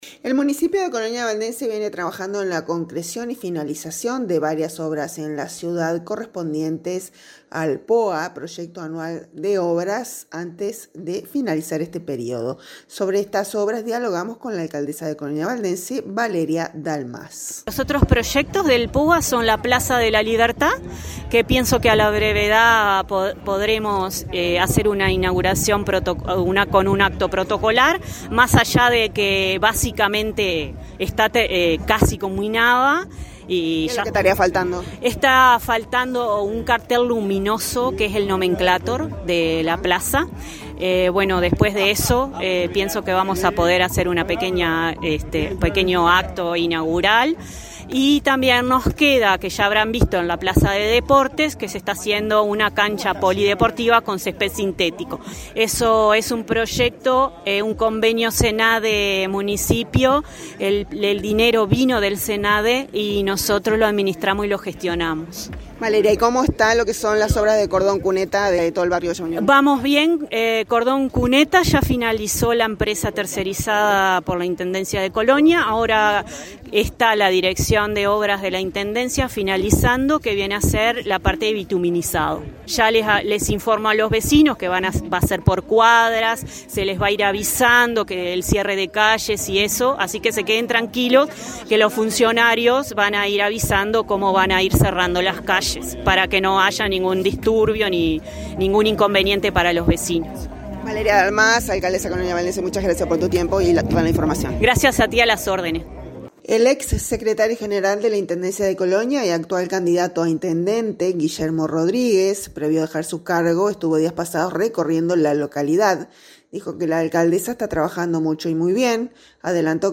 Sobre todas estas obras, dialogamos con la Alcaldesa de C. Valdense, Valeria Dalmás.